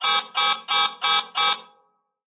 LockroomSiren.ogg